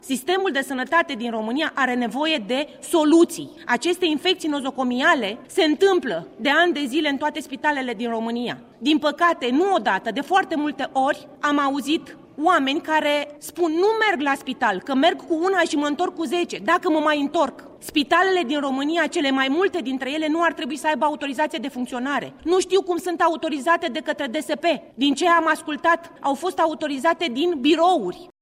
Deputata AUR, Geanina Şerban: